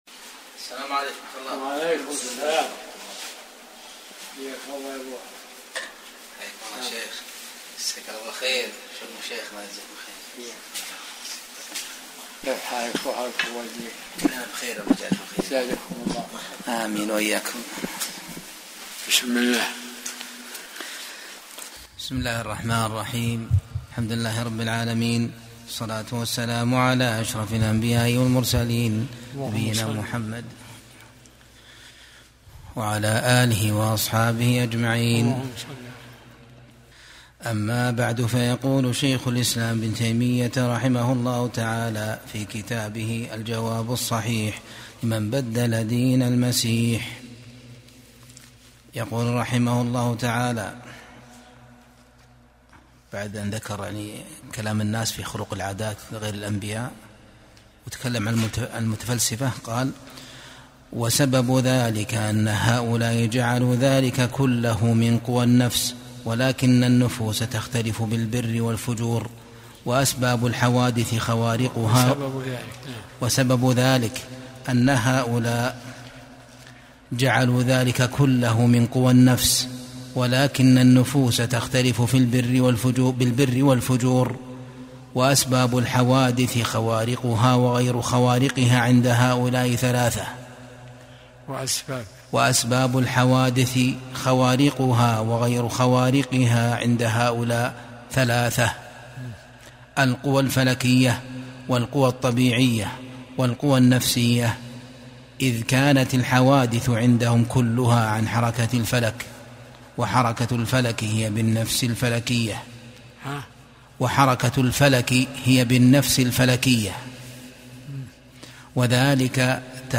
درس الأربعاء 57